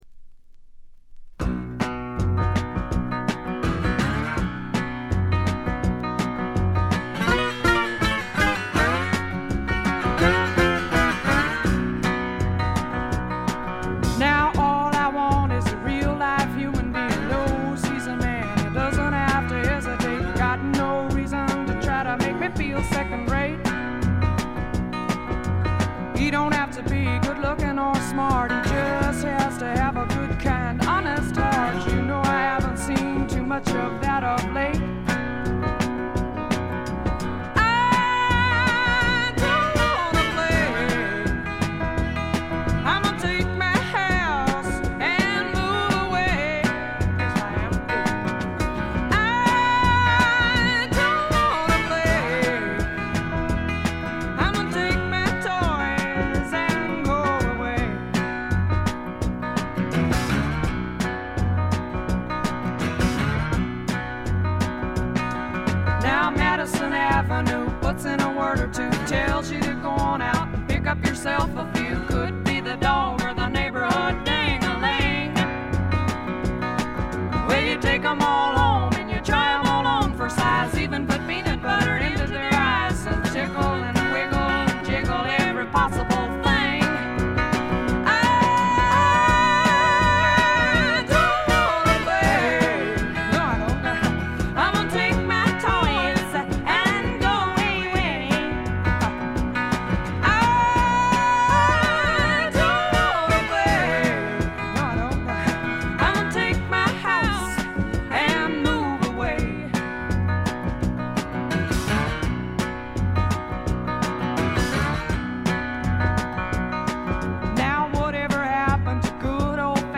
試聴曲は現品からの取り込み音源です。
Recorded At: The Record Plant East, New York City.